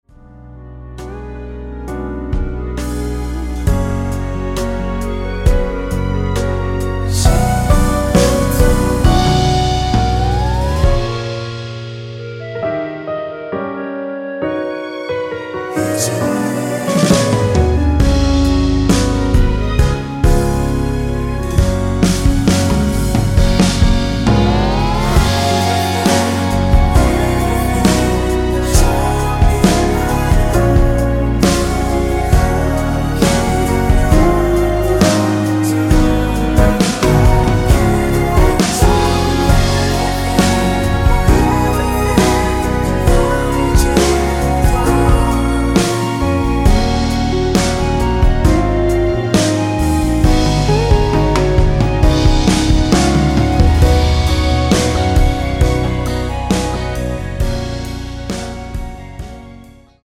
원키에서(-1)내린 멜로디와 코러스 포함된 MR입니다.(미리듣기 확인)
Gb
◈ 곡명 옆 (-1)은 반음 내림, (+1)은 반음 올림 입니다.
앞부분30초, 뒷부분30초씩 편집해서 올려 드리고 있습니다.